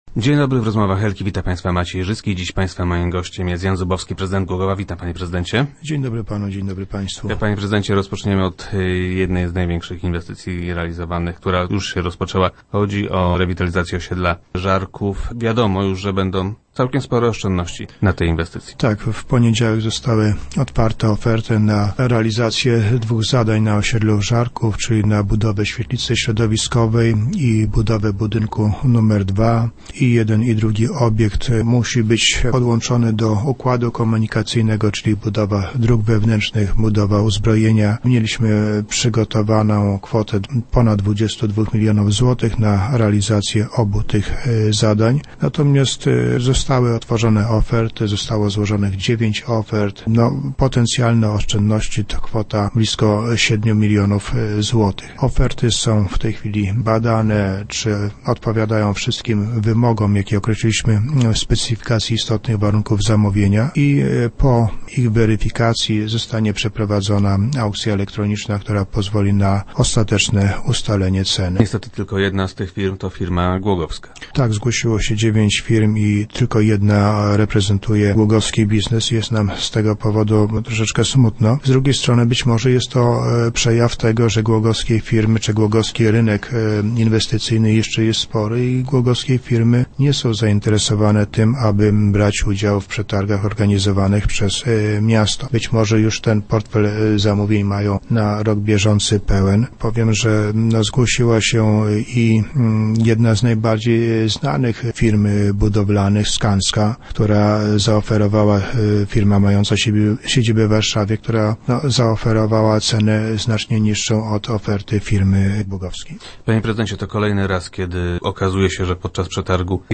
- Najtańsza oferta jest o niemal siedem milionów mniejsza od kwoty, którą na to zadanie przeznaczyliśmy  - informuje prezydent Jan Zubowski, który był gościem Rozmów Elki.